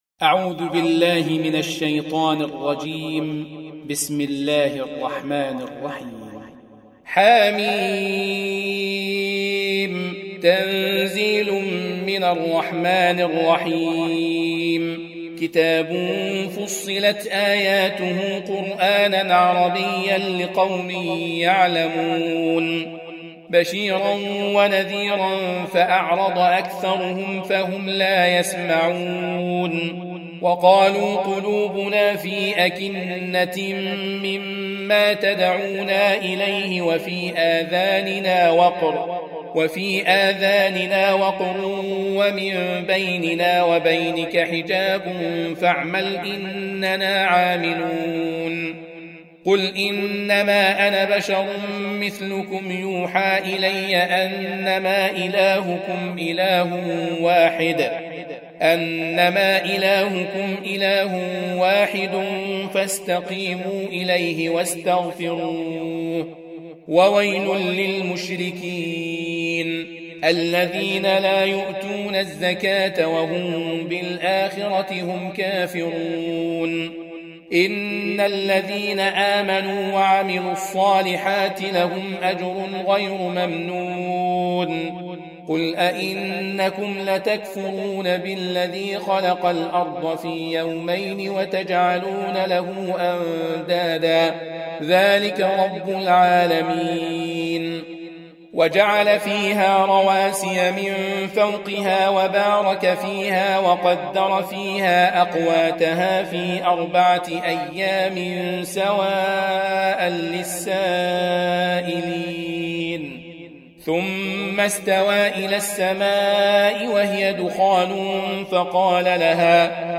Surah Repeating تكرار السورة Download Surah حمّل السورة Reciting Murattalah Audio for 41. Surah Fussilat سورة فصّلت N.B *Surah Includes Al-Basmalah Reciters Sequents تتابع التلاوات Reciters Repeats تكرار التلاوات